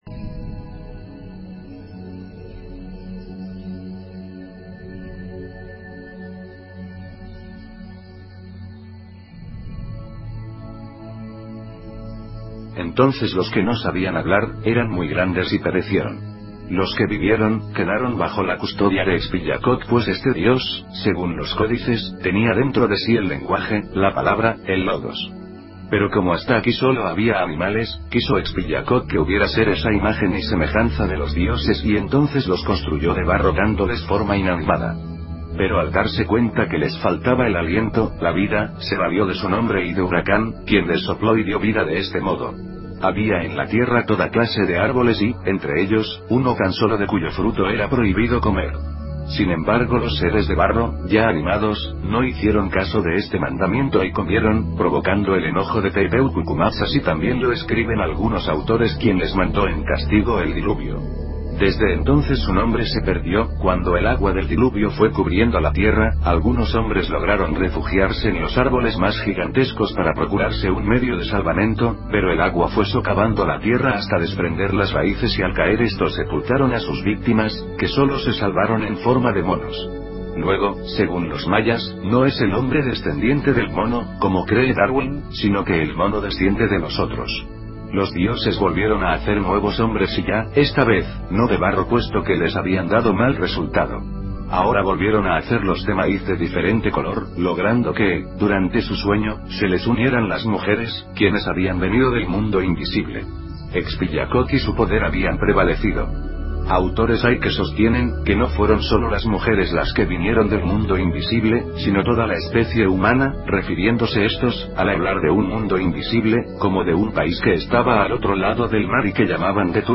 Audio Libro